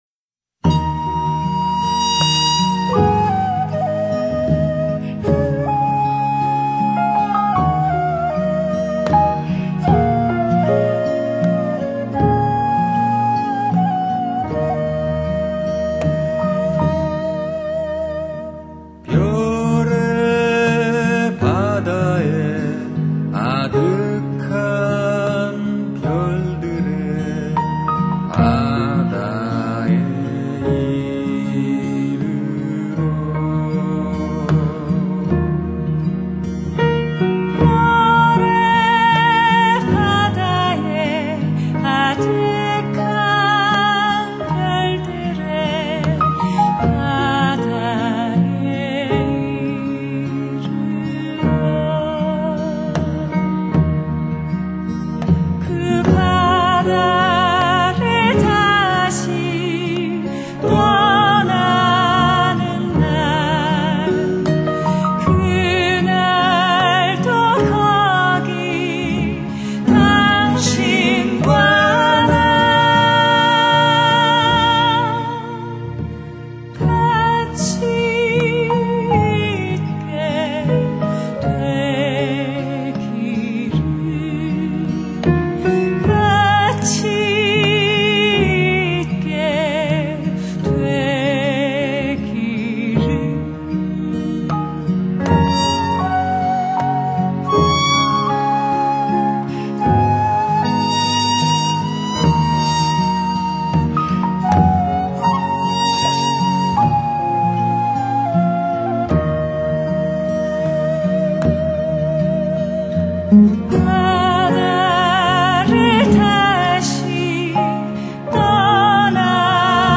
音乐类型：Folk
韩国本土音乐一般都是舒缓、流畅的曲子，
舒缓的音乐!